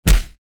face_hit_small_13.wav